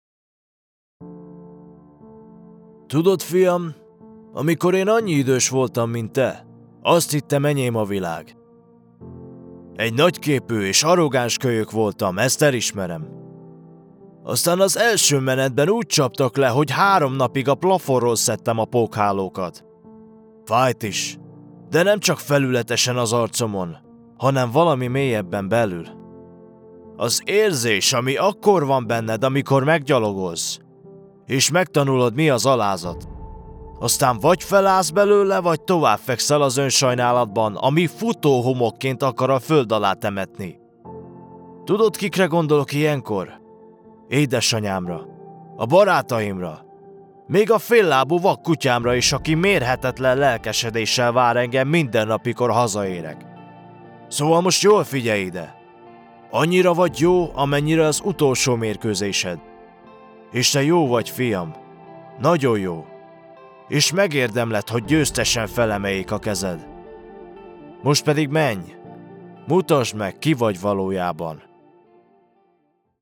Male
Adult (30-50)
My voice is warm, relaxed, clear and versatile, with a natural mid-to-deep tone that works perfectly for commercials, corporate narration, e-learning, audiobooks and character work.
Character / Cartoon
Powerful Dramatic Monologue
All our voice actors have professional broadcast quality recording studios.
1002HungarianCharacterDemo.mp3